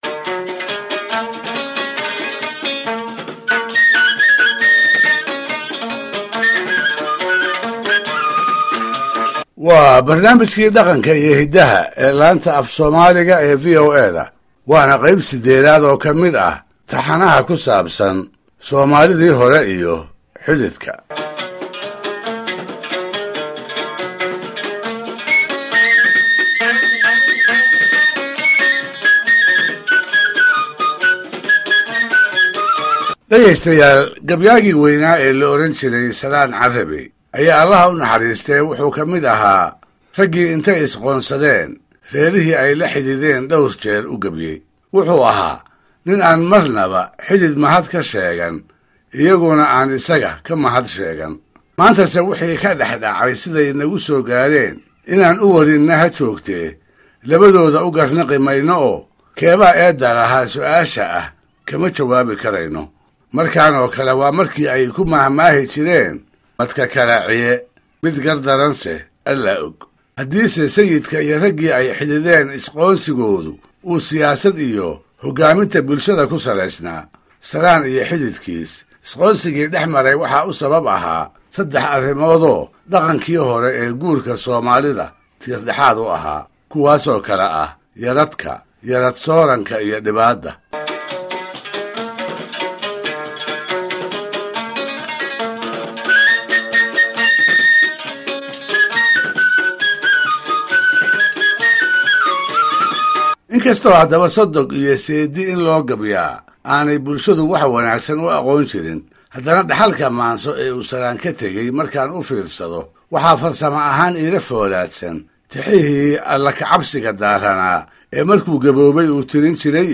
Barnaamijka oo uu soo jeediyo Axmed Faarax Cali (Idaajaa), hoosta ka dhageyso.